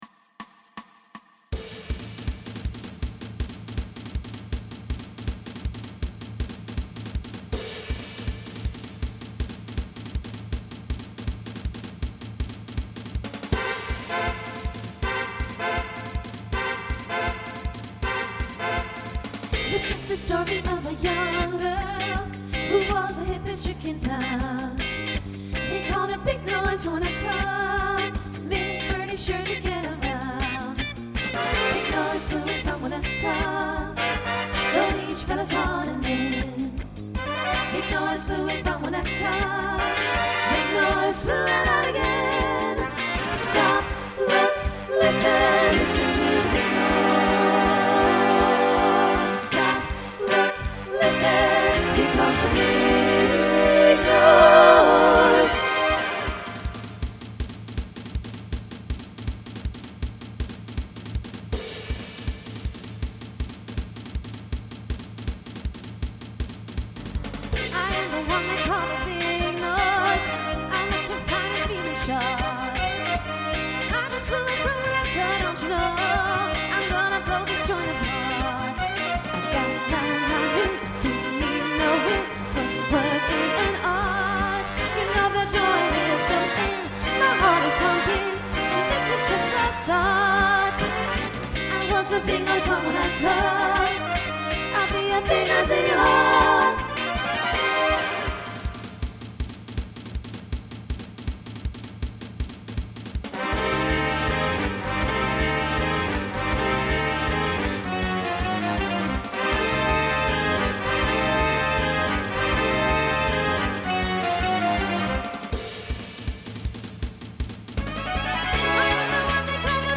Voicing SSA Instrumental combo Genre Swing/Jazz
Mid-tempo